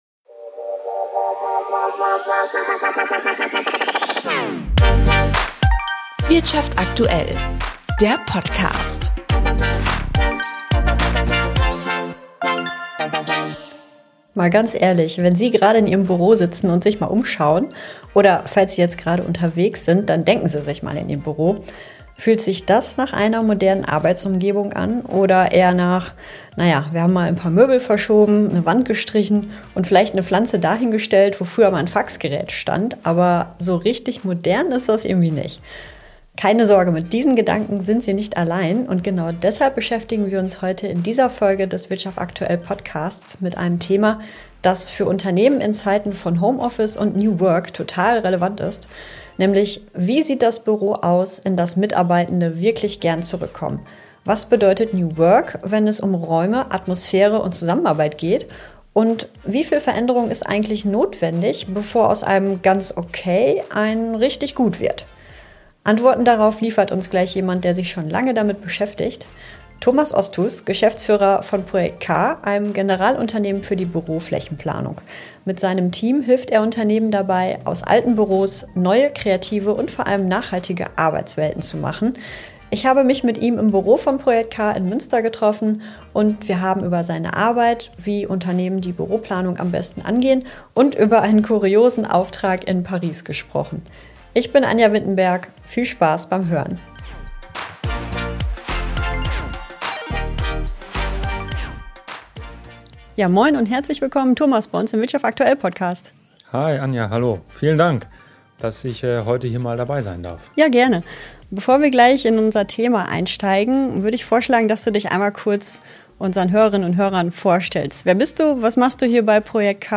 Früher Büro, heute Arbeitswelt – ein Gespräch